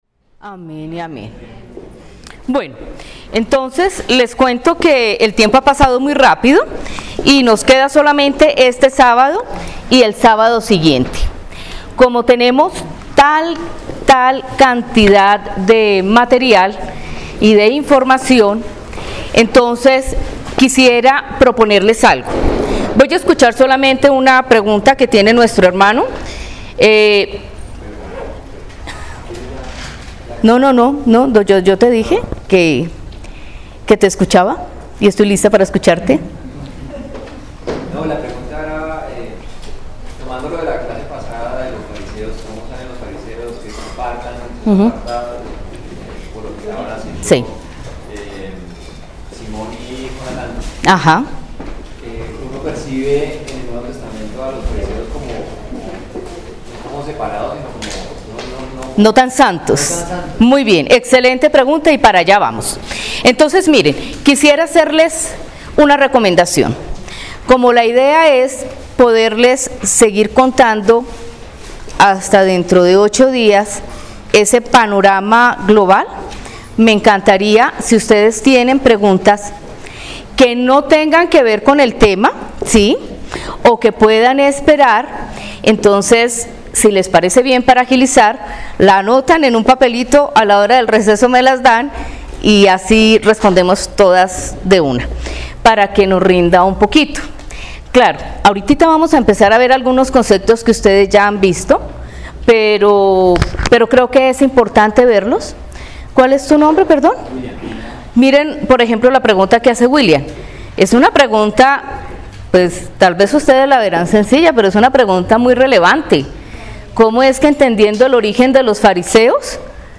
Lección 1: Introducción a la historia del cristianismo V (Marzo 17, 2018)